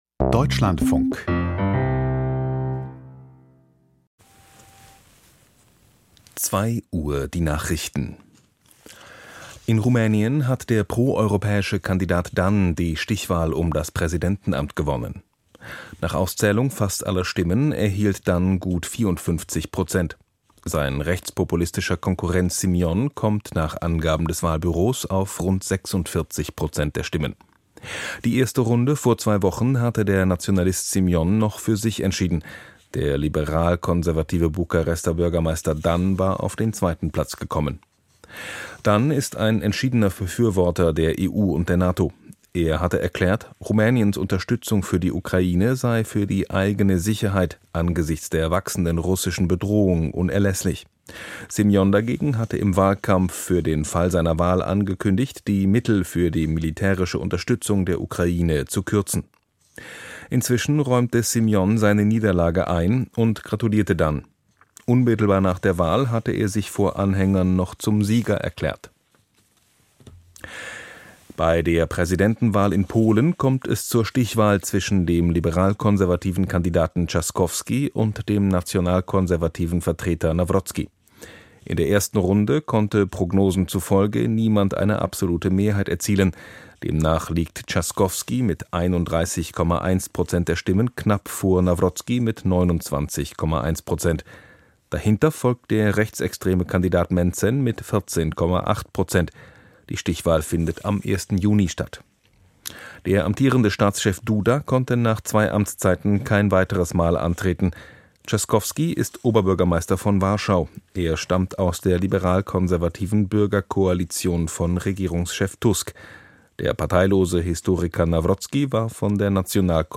Die Nachrichten vom 19.05.2025, 01:59 Uhr